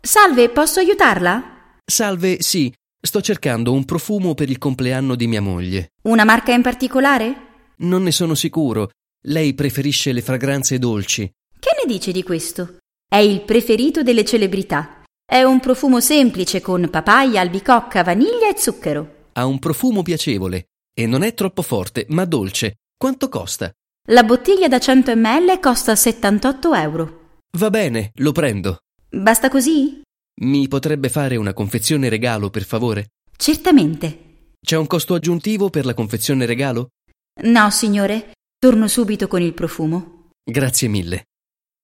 HASZNOS OLASZ PÁRBESZÉD: Ajándékcsomagolás kérése
hasznos párbeszédek audióval
chiedere-una-confezione-regalo.mp3